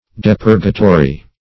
Search Result for " depurgatory" : The Collaborative International Dictionary of English v.0.48: Depurgatory \De*pur"ga*to*ry\, a. Serving to purge; tending to cleanse or purify.